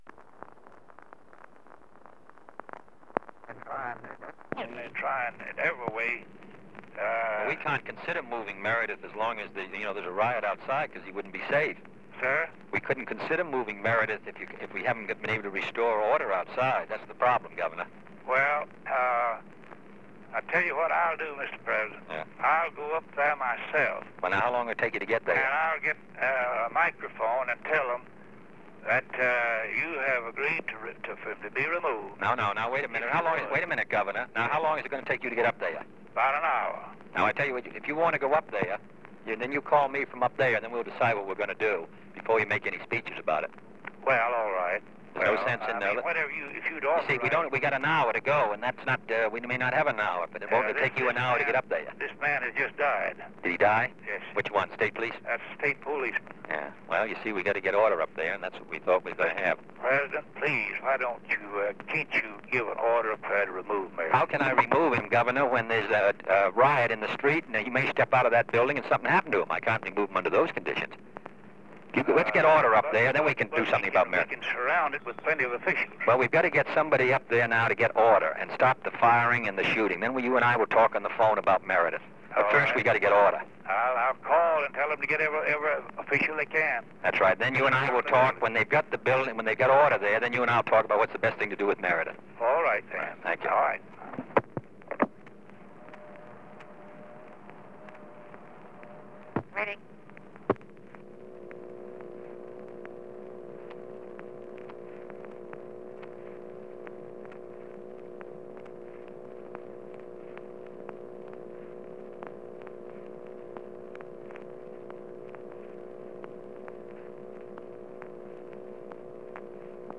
Conversation with Ross Barnett (12:14 am cont.)
Secret White House Tapes | John F. Kennedy Presidency Conversation with Ross Barnett (12:14 am cont.)